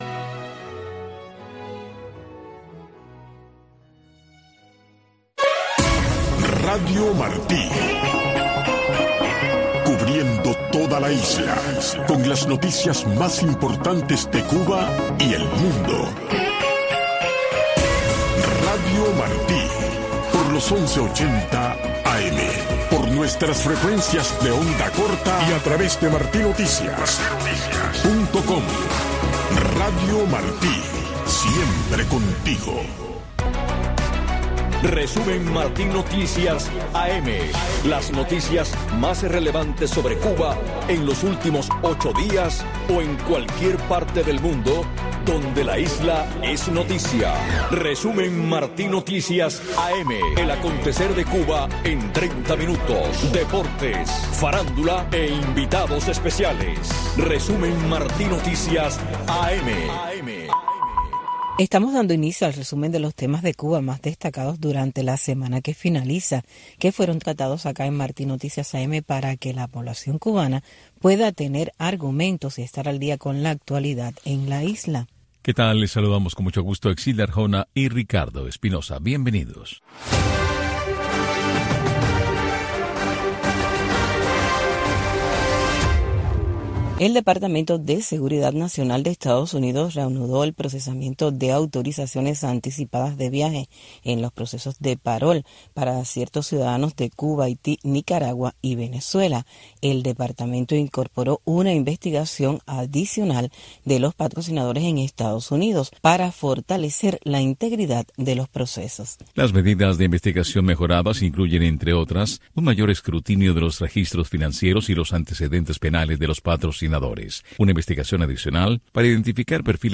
Un resumen ágil y variado con las noticias más relevantes que han ocurrido en Cuba en los últimos 8 días o en cualquier parte del mundo donde un tema sobre la isla es noticia, tratados con invitados especiales. Media hora de información, deportes y farándula.